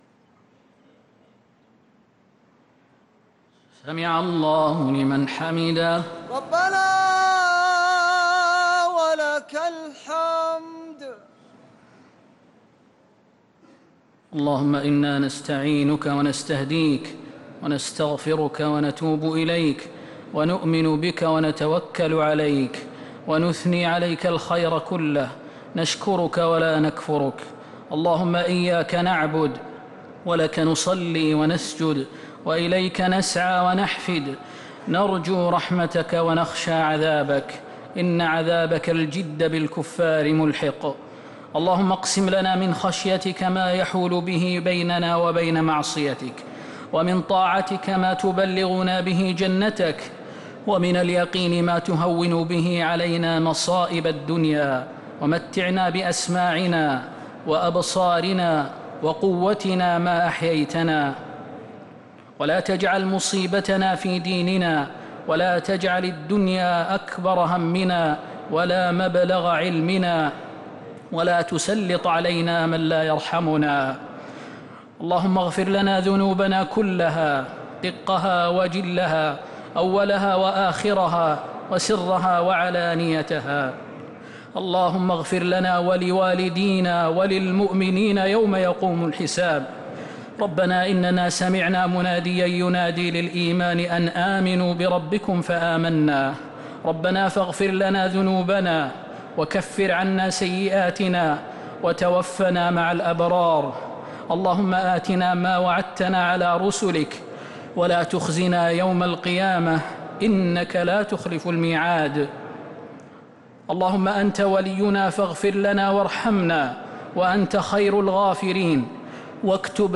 دعاء القنوت ليلة 15 رمضان 1447هـ | Dua for the night of 15 Ramadan 1447H > تراويح الحرم النبوي عام 1447 🕌 > التراويح - تلاوات الحرمين